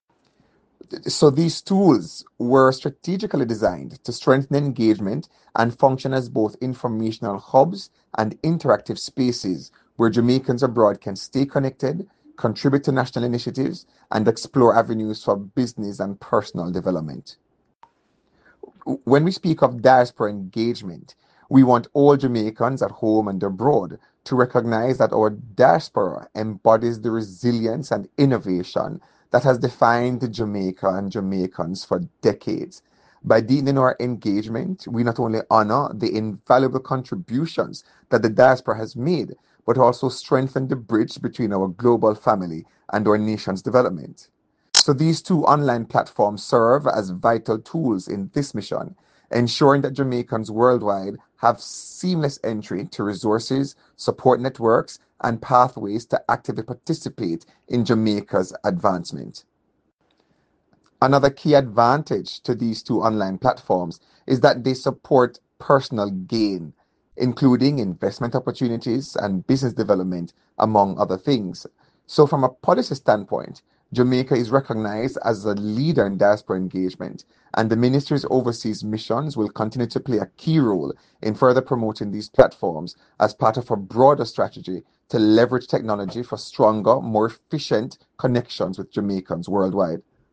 The Minister shared this update during a sensitisation session held at the Ministry, which focused on improving awareness and use of two key digital platforms: JaDiasporaEngage (JA-DEM, formerly JAM-DEM) and ConnectMeJa (Diaspora Registration Portal).
Minister-Terrelonge-Speaks-on-JA-DEM-and-ConnectMeJa.mp3